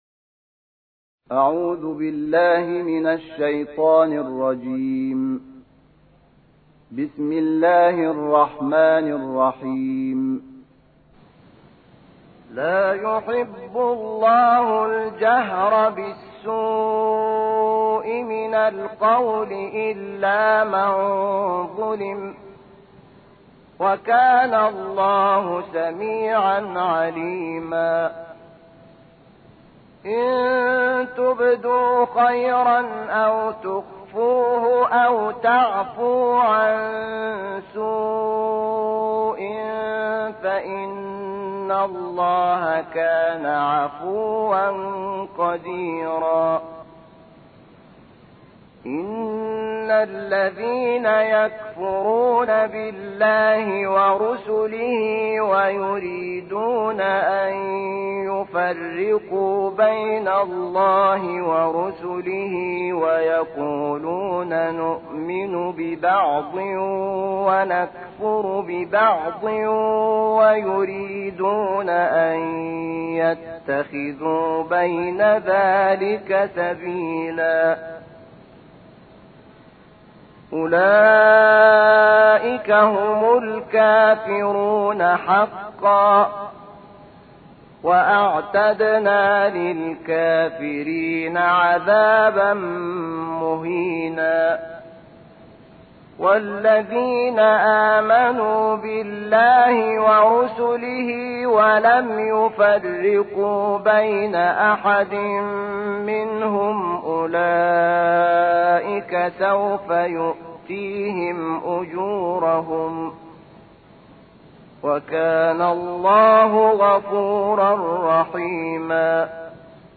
ترتیل جزء ششم قرآن با صدای شحات انور
فایل صوتی ترتیل جزء ششم قرآن‌کریم با صدای استاد شحات انور در اینجا قابل دریافت است.